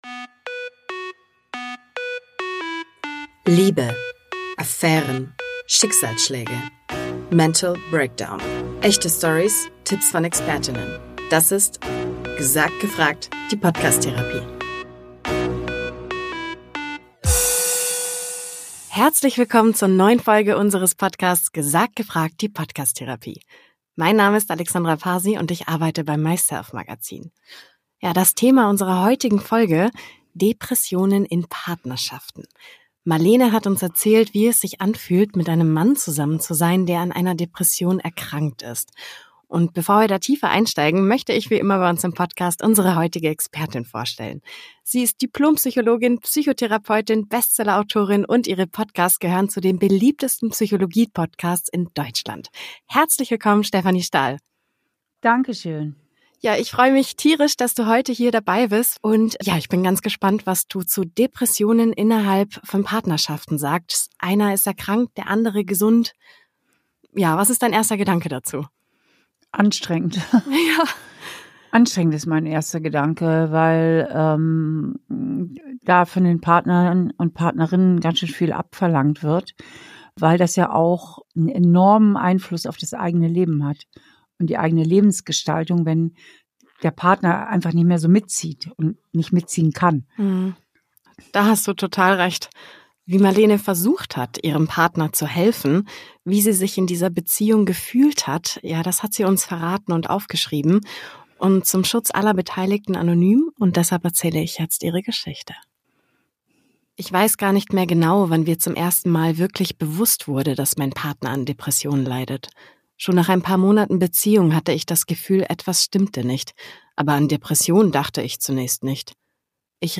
#14 Mein Mann hat Depressionen | Psychologin Stefanie Stahl ~ Gesagt. Gefragt - Die Podcast-Therapie Podcast